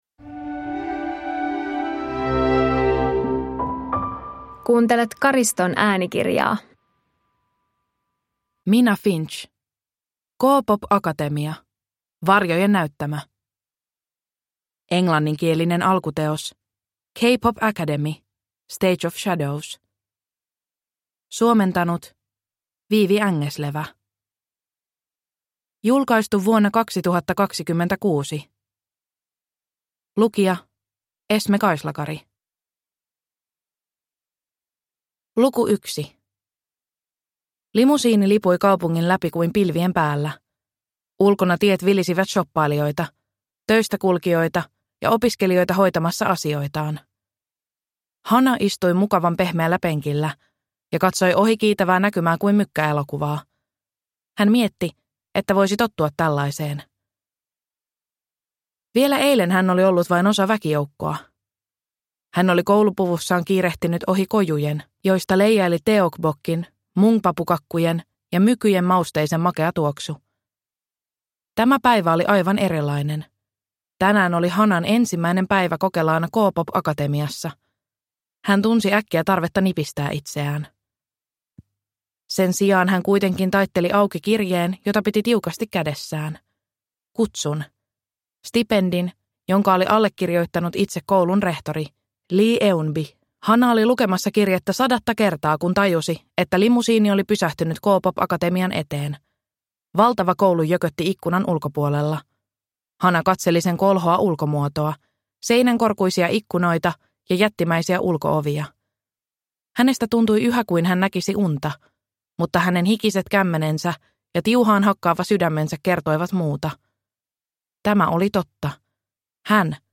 K-pop-akatemia – Varjojen näyttämö – Ljudbok